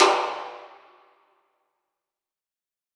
WATERTANKI.wav